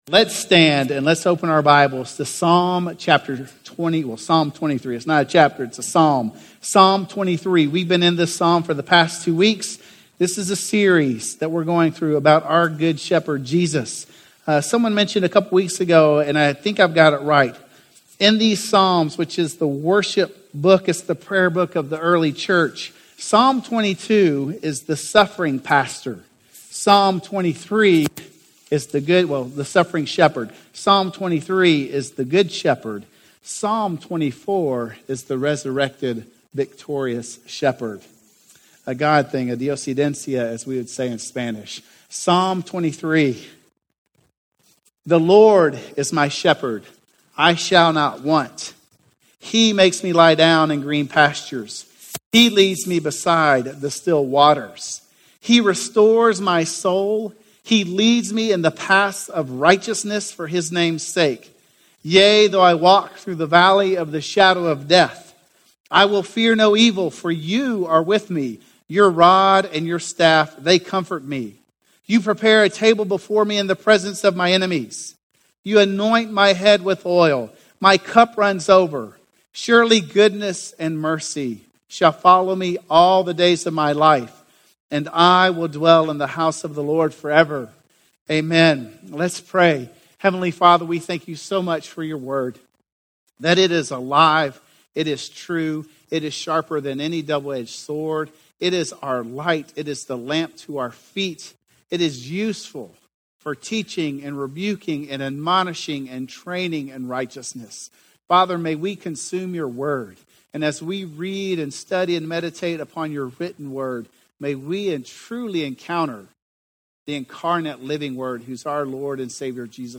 Dealing With Lonliness - Sermon - Woodbine